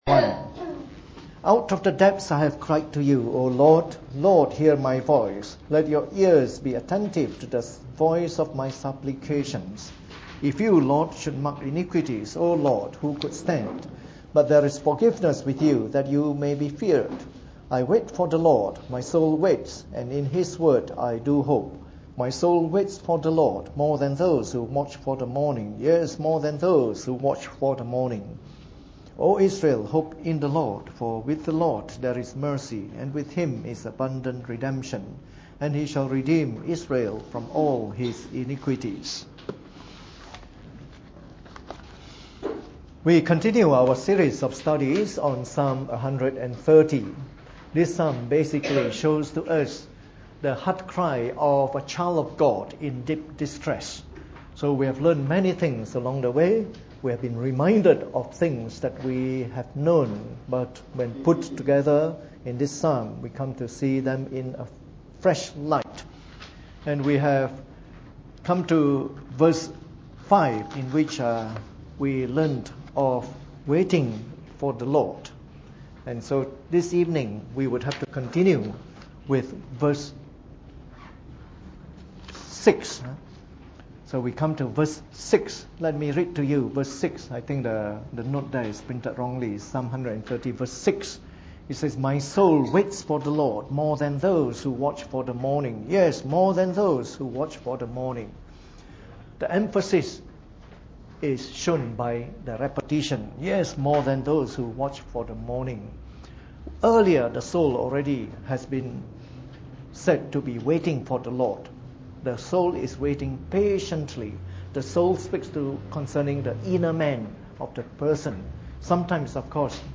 Preached on the 28th of August 2013 during the Bible Study, from our series of talks on Psalm 130.